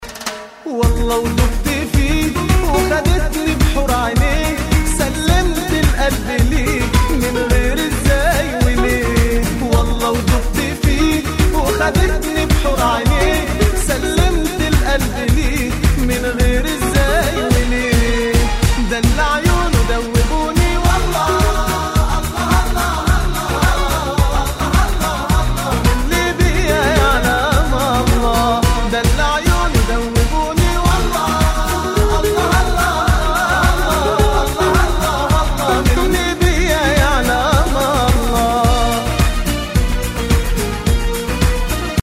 • Качество: 128, Stereo
инструментальные
восточные
арабские